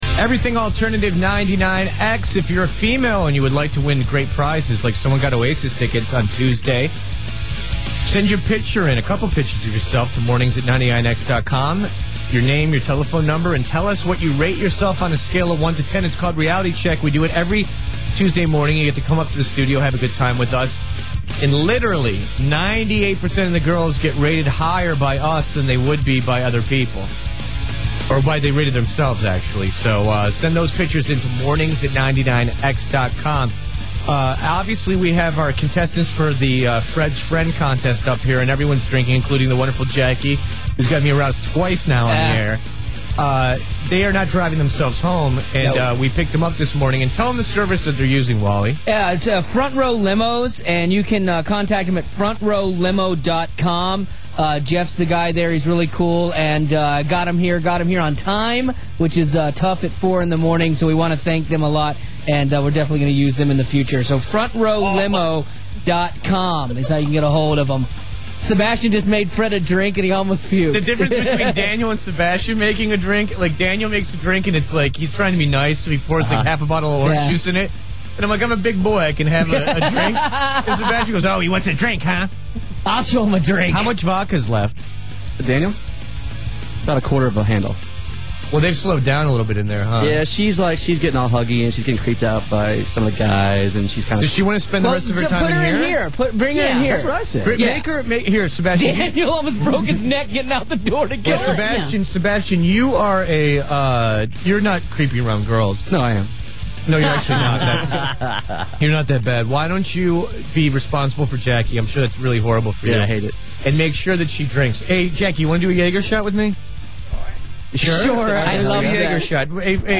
Today the final contestants visited the studio.